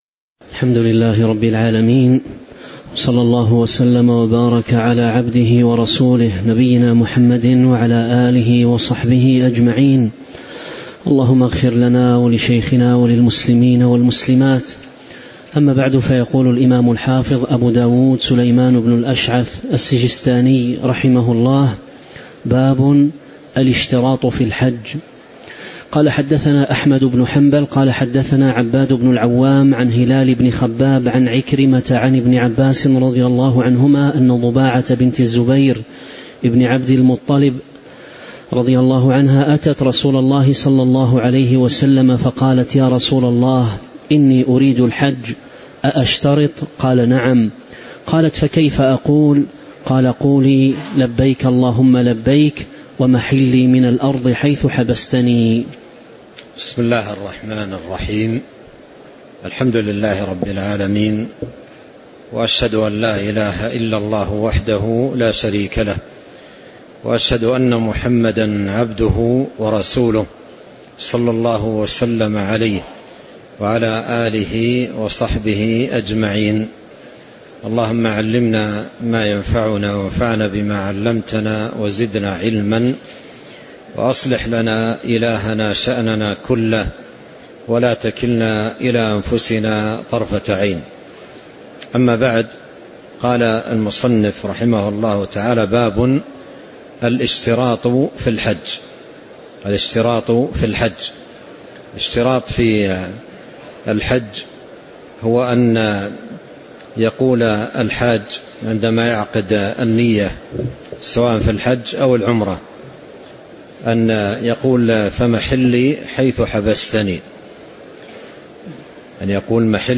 تاريخ النشر ٢٥ ذو القعدة ١٤٤٦ المكان: المسجد النبوي الشيخ